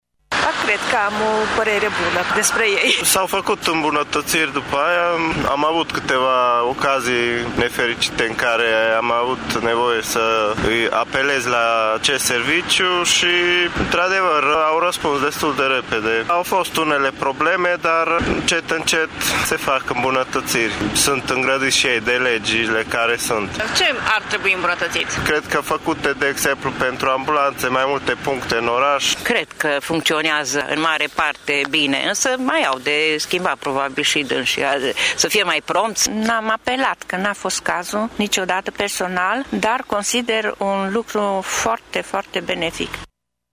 Târgumureșenii cred că după incendiul de la Colectiv Serviciul 112 s-a îmbunătățit iar extinderea lui este absolut necesară: